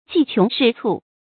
计穷势蹙 jì qióng shì cù
计穷势蹙发音